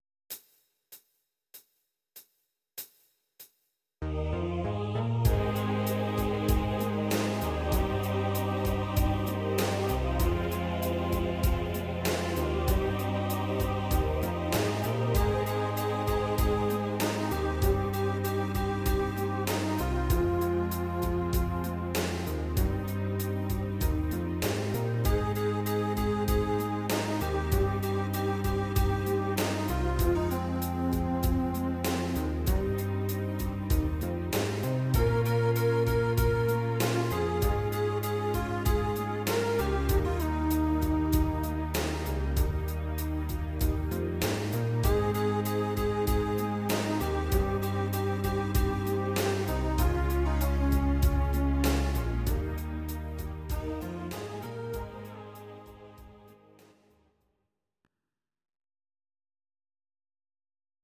Audio Recordings based on Midi-files
Pop, Musical/Film/TV, 1980s